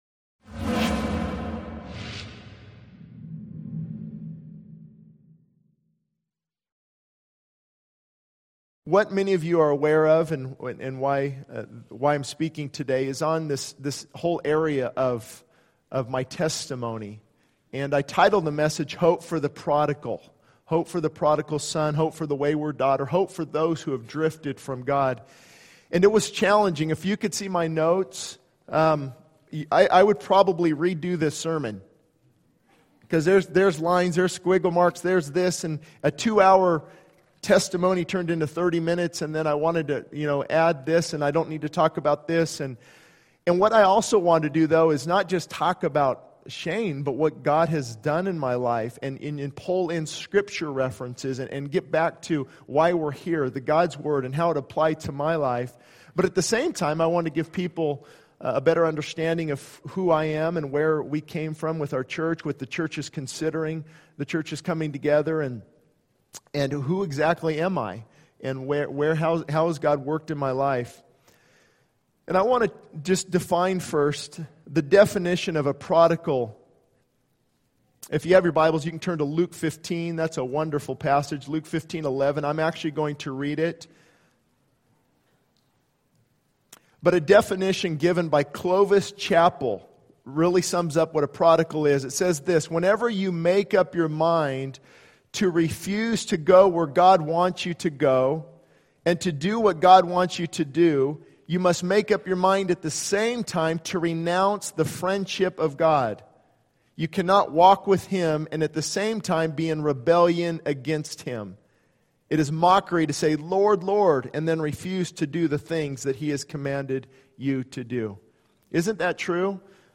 This sermon is a personal testimony focusing on the journey of the speaker, highlighting the struggles, brokenness, and redirection by God in his life. It emphasizes the importance of repentance, humility, and surrender to God, showcasing the hope found in returning to Him. The speaker shares about the prodigal son story, urging individuals to come home to God and experience His compassion and restoration.